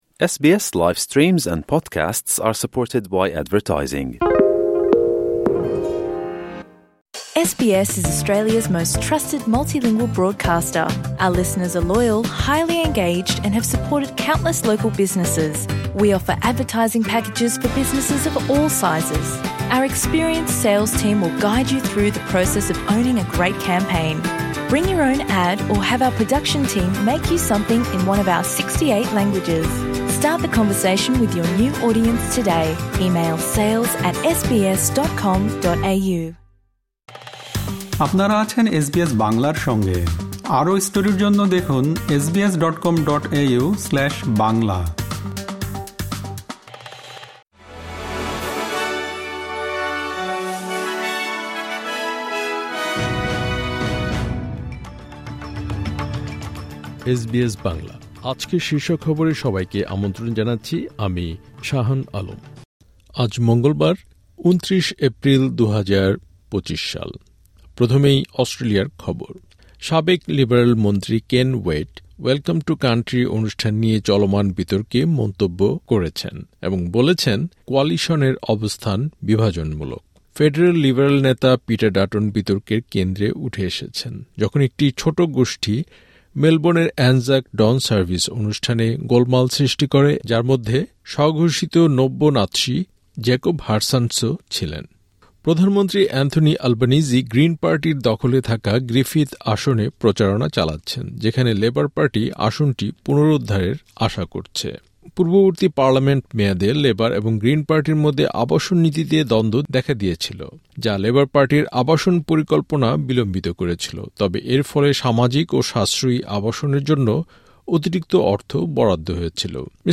এসবিএস বাংলা শীর্ষ খবর: ২৯ এপ্রিল, ২০২৫